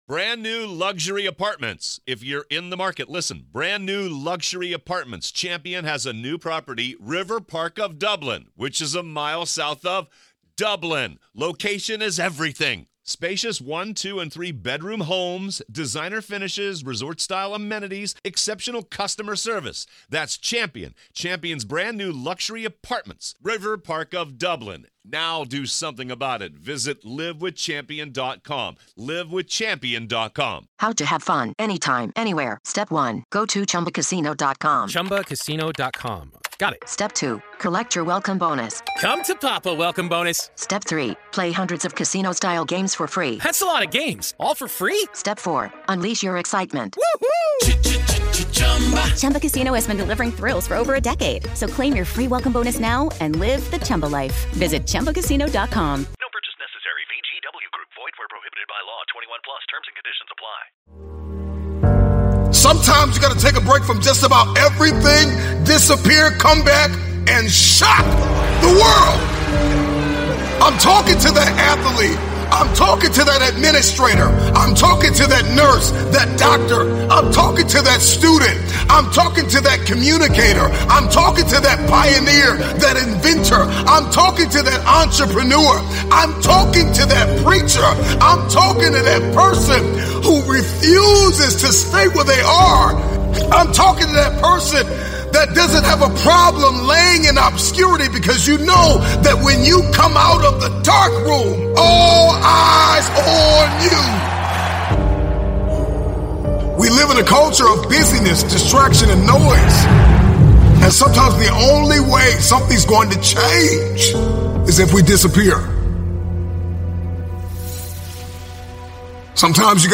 BEST MOTIVATIONAL SPEECH - (BEST OF 2022 SO FAR)